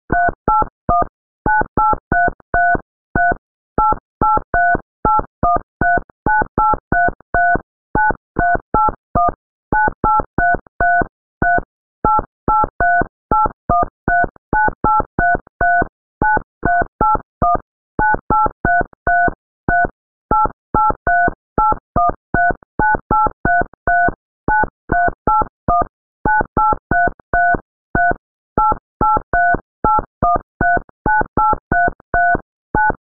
Ringtone with voice notification.